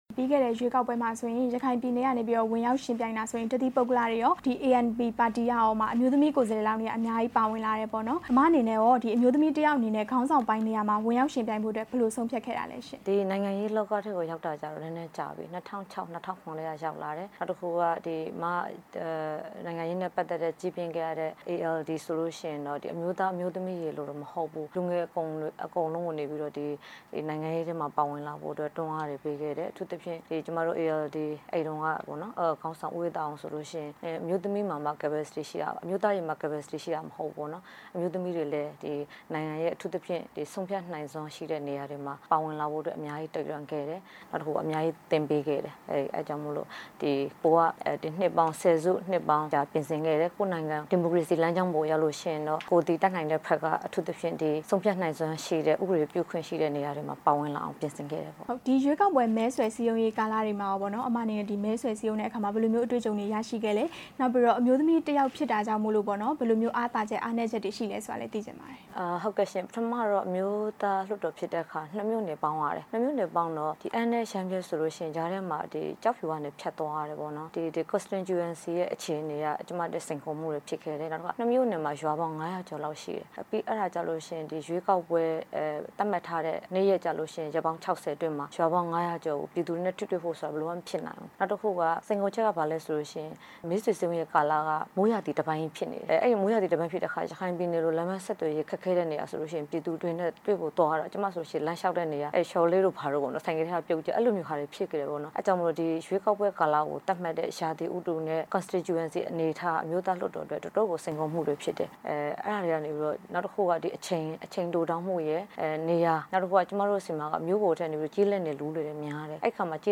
ANP လွှတ်တော် ကိုယ်စားလှယ် ဒေါ်ထုမေ နဲ့ မေးမြန်းချက်